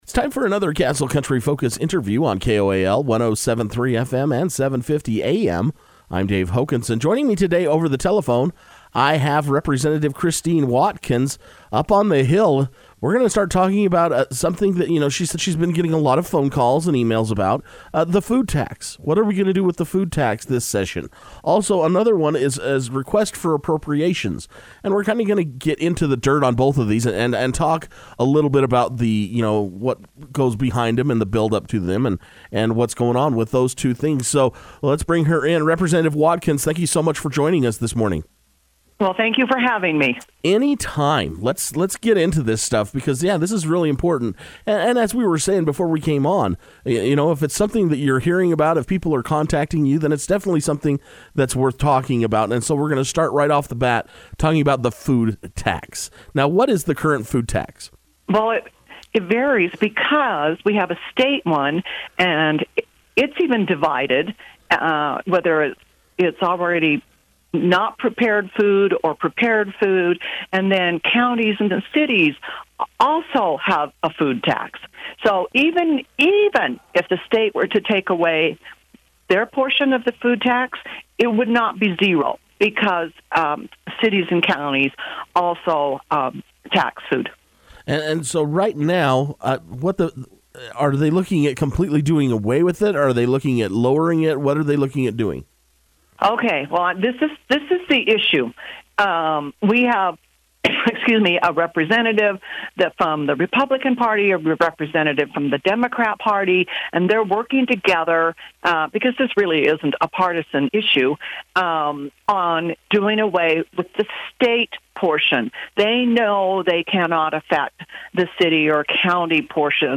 Things are moving along at the Utah Legislative Session and once again Castle Country Radio spoke over the telephone with Representative Christine F. Watkins on Thursday morning to get another update this week.
Individuals can listen to the entire interview with Representative Christine F. Watkins by clicking the link below